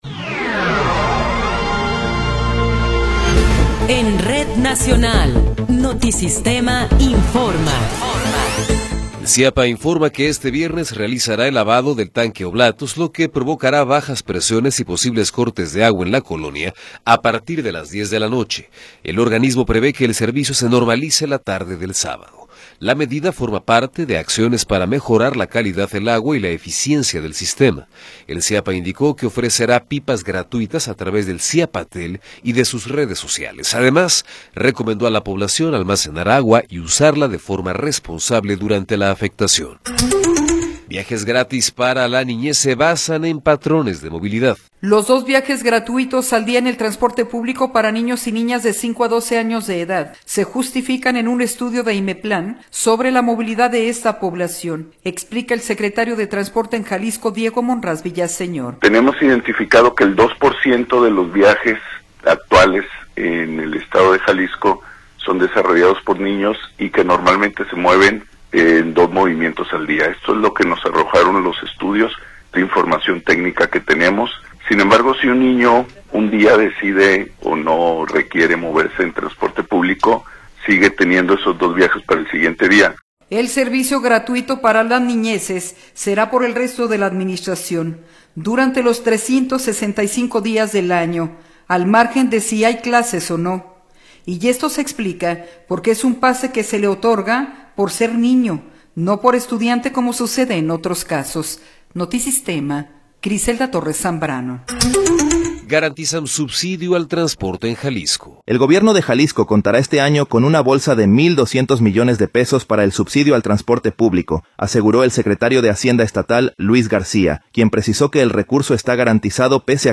Noticiero 12 hrs. – 16 de Abril de 2026
Resumen informativo Notisistema, la mejor y más completa información cada hora en la hora.